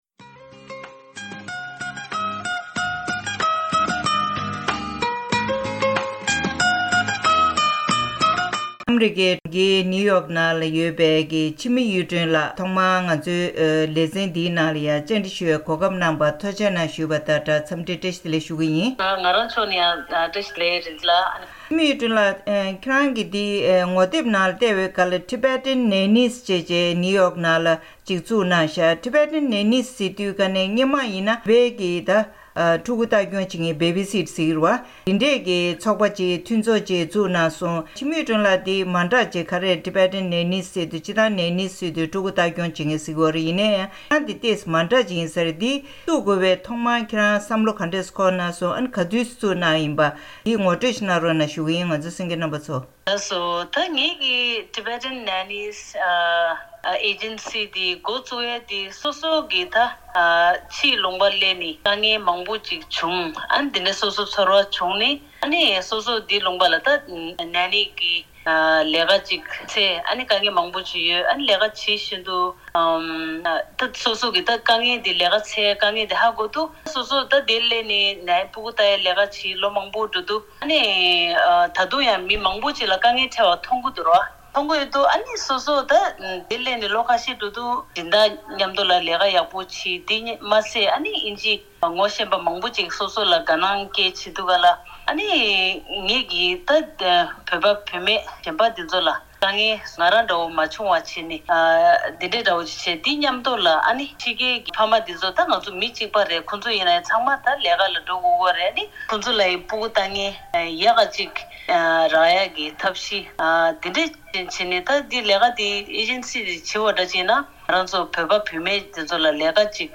ཨ་རིའི་གྲོང་ཁྱེར་ནིའུ་ཡོག་ཏུ་ཡོད་པའི་Tibetan Nanniesཞེས་པའི་ཚོགས་པ་བཙུགས་དགོས་པའི་དམིགས་ཡུལ་དང་ཕན་ཐོགས་སྐོར་བཅར་འདྲི་ཞུས་པ།